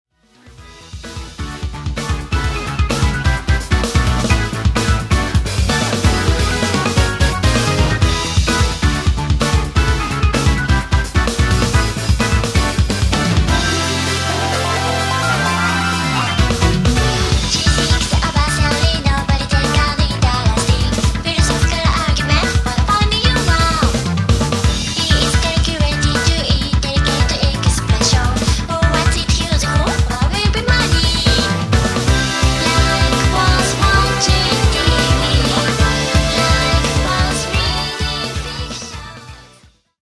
ジャケットイラスト(の一部) 今回はフルボーカルのミニアルバムでございます。